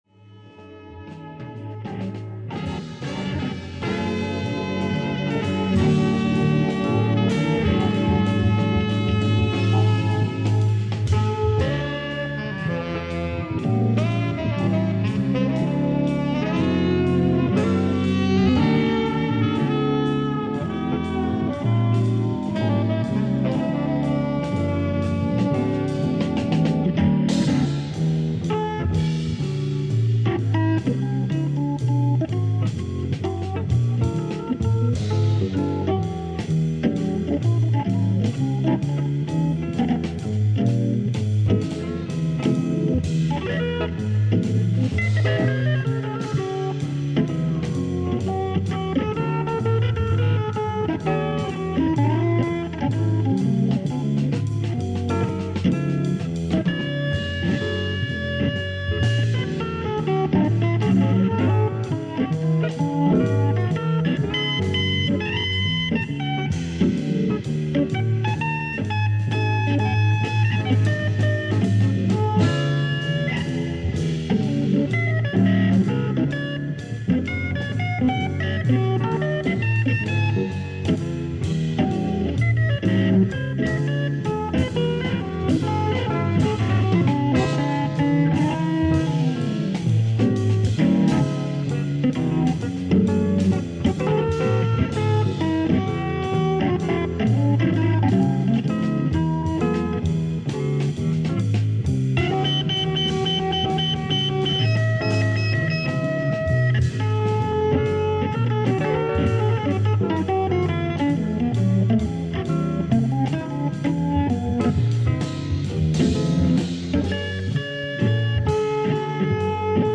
Hammondorgel